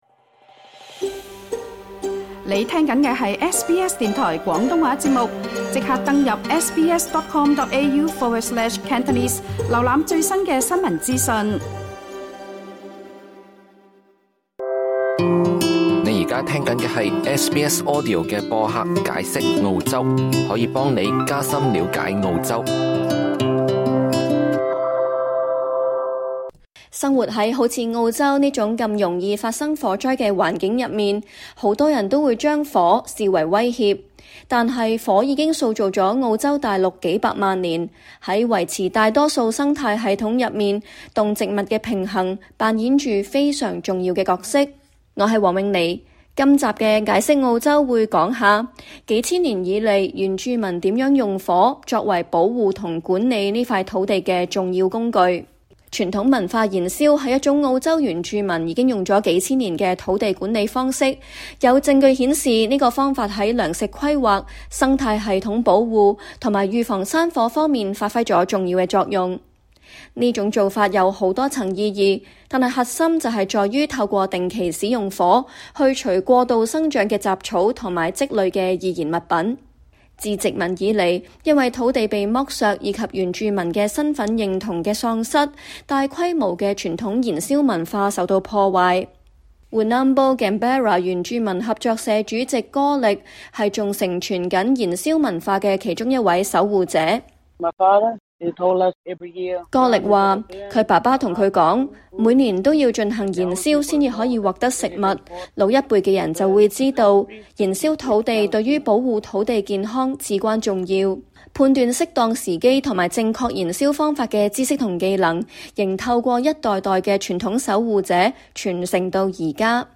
學者和消防管理專業人士就分享了他們對這原住民傳統知識的看法。